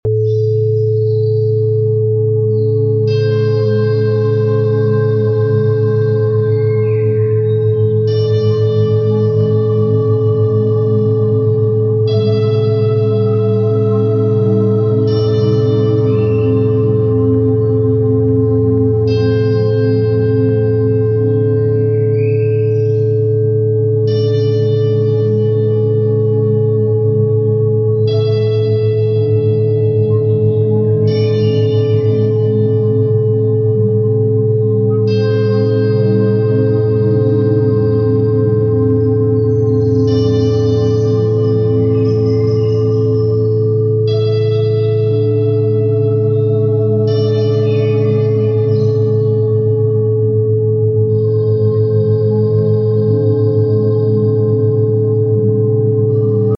111 HZ 432 HZ Sueño Sound Effects Free Download
111 HZ- 432 HZ Sueño profundo Sumérgete en un oasis de paz con este video diseñado para brindarte relajación profunda y un sueño reparador. Siente cómo la frecuencia de 111Hz enciende tu energía vital y se entrelaza armoniosamente con la frecuencia de 432Hz, conocida por sus propiedades curativas y su capacidad para inducir un estado de calma total. Permite que estas ondas sonoras te envuelvan suavemente, liberando tensiones y preparando tu mente y cuerpo para un descanso profundo y rejuvenecedor.